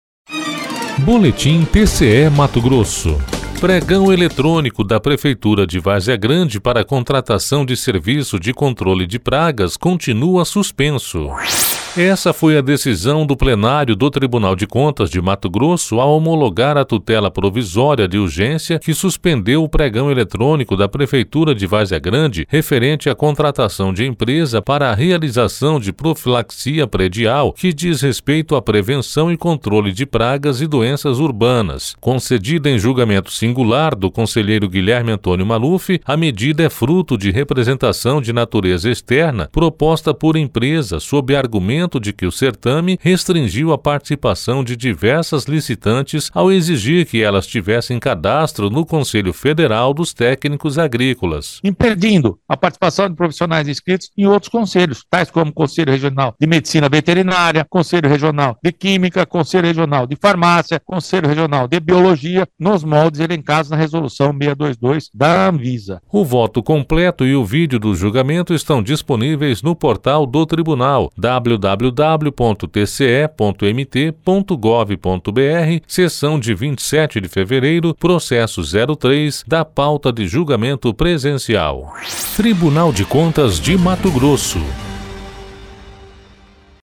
Sonora: Guilherme Antonio Maluf – conselheiro do TCE-MT